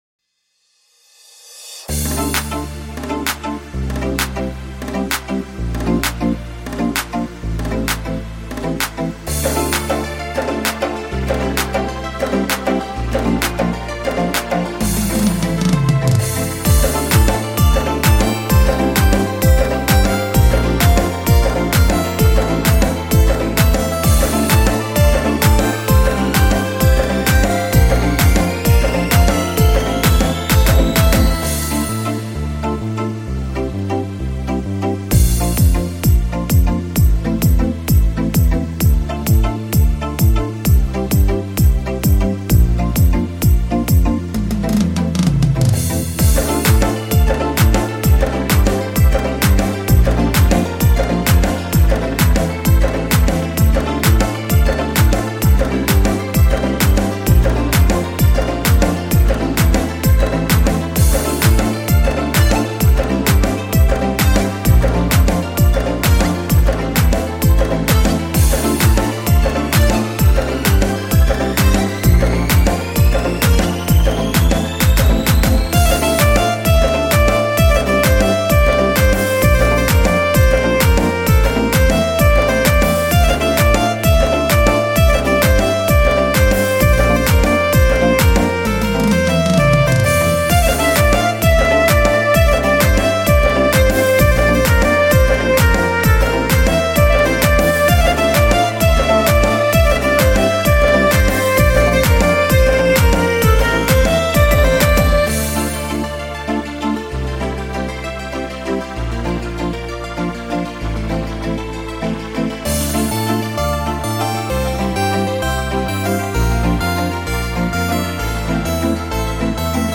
Минусовка